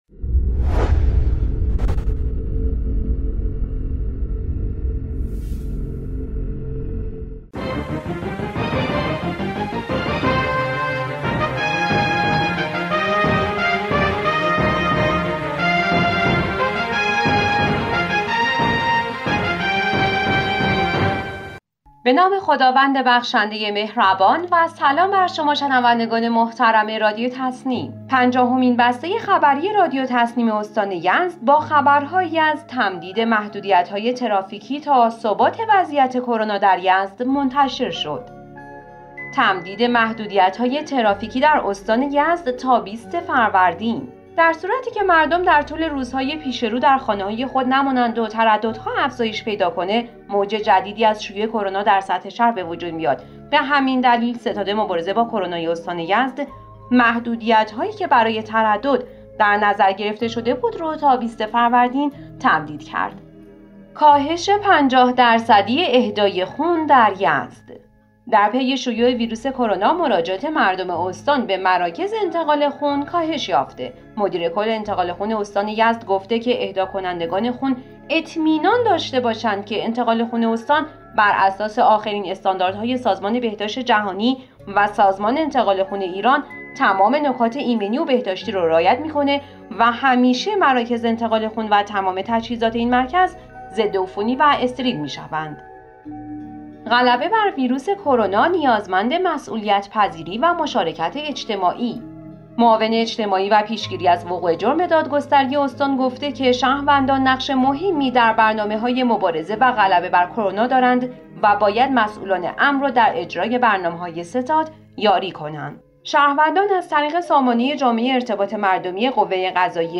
به گزارش خبرگزاری تسنیم از یزد, پنجاهمین بسته خبری رادیو تسنیم استان یزد با خبرهایی از تمدید محدودیت‌های ترافیکی استان یزد, کاهش 50درصدی اهدای خون در استان, مسئولیت کنار مشارکت اجتماعی عامل غلبه بر کرونا, اختصاص یکصد میلیون ریال از درآمد موقوفات اردکان برای مبارزه کرونا و راه‌اندازی سامانه غربالگری استرس ناشی از کرونا منتشر شد.